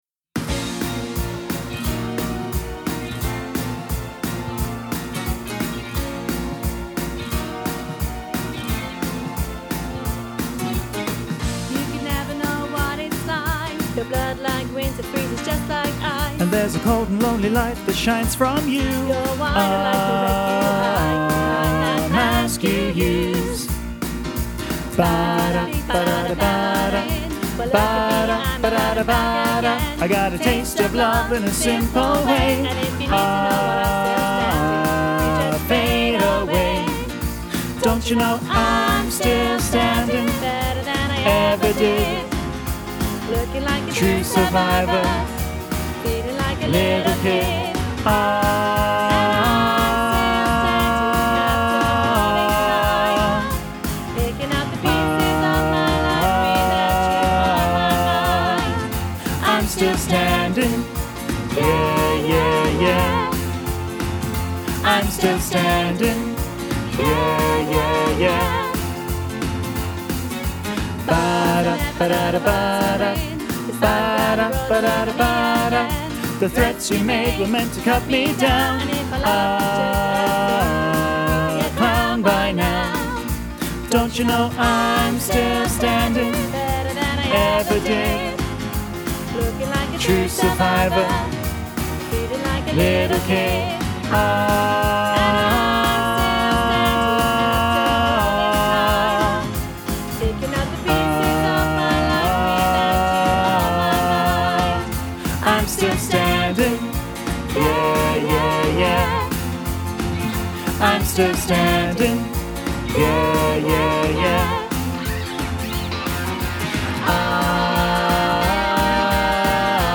Listen to bass track with soprano and alto accompaniment